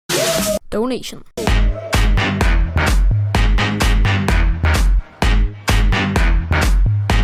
twitch-donation-sound-1.mp3